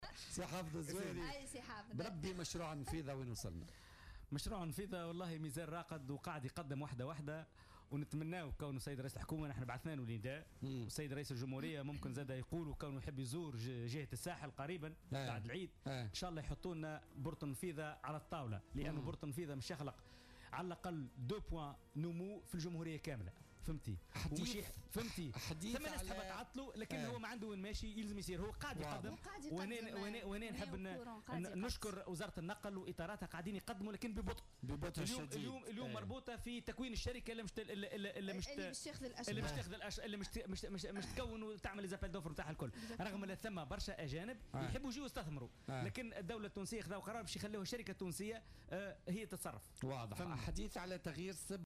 أكد النائب عن افاق تونس حافظ الزواري ضيف بولتيكا اليوم الإثنين أن مشروع النفيضة يتقدم بخطى بطيئة مؤكدا أن رئيس الحكومة باش يزور الجهة قريبا وسيضع مشروع ميناء النفيضة على الطاولة .